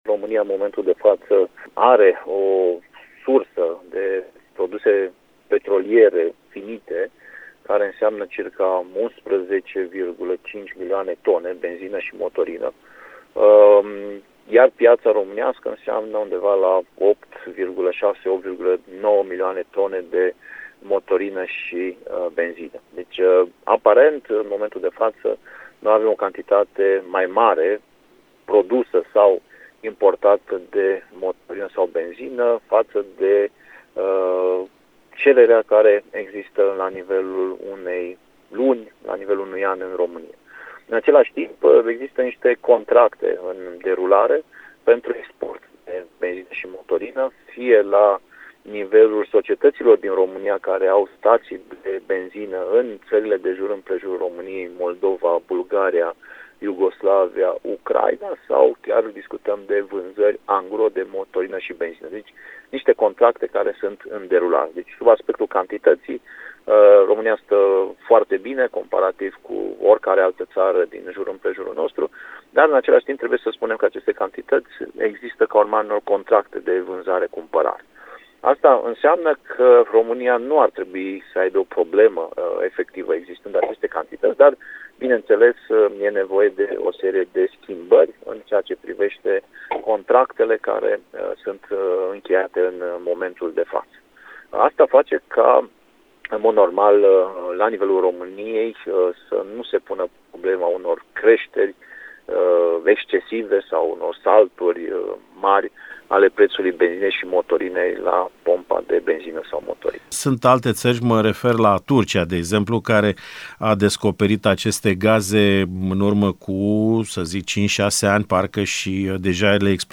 Subiectul a fost abordat și în emisiunea „Dialoguri la zi” de astăzi, din care redăm un fragment.